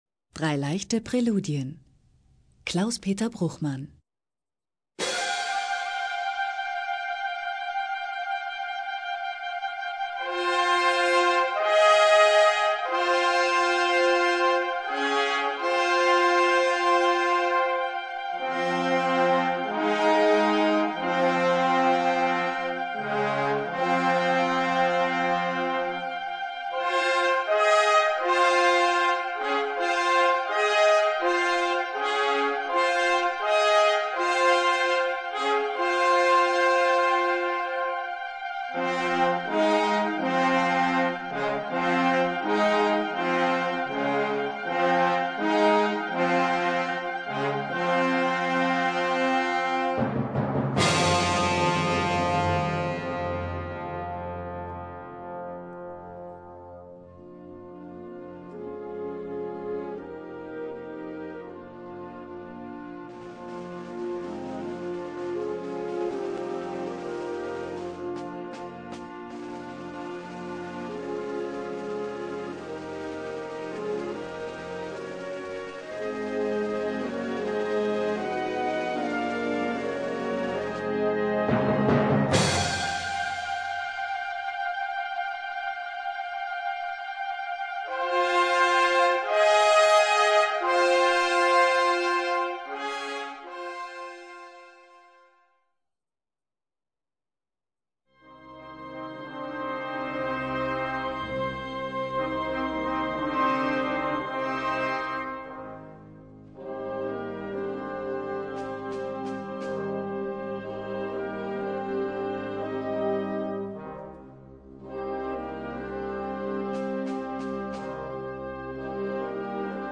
Gattung: Kirchliche Blasmusik
Besetzung: Blasorchester